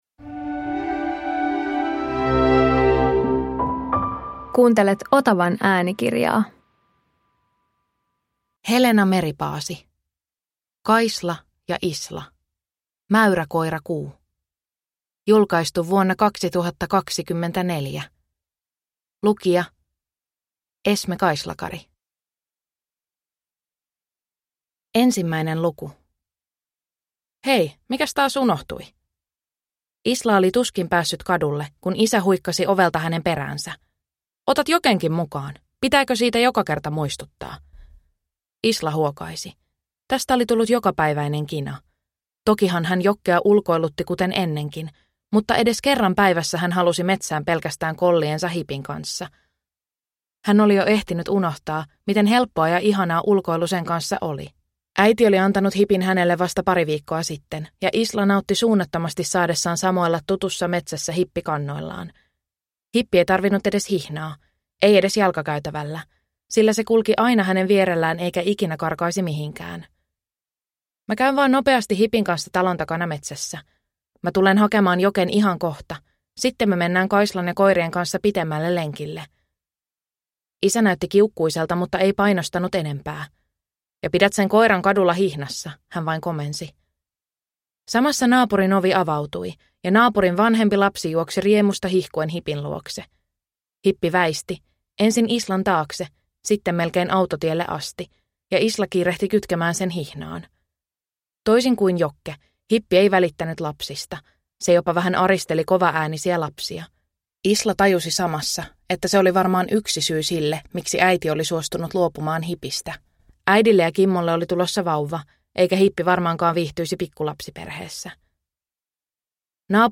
Kaisla ja Isla - Mäyräkoirakuu – Ljudbok